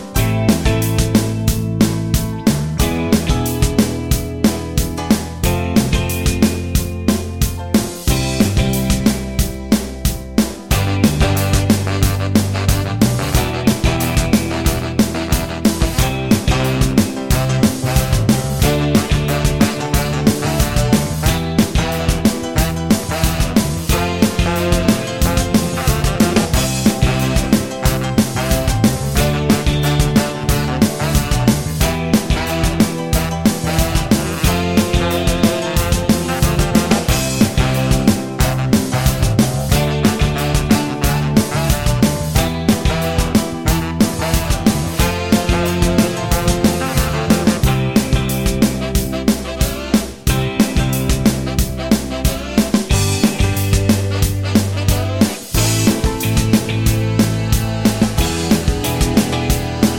No Sax Solo And No Backing Vocals Pop (1980s) 3:53 Buy £1.50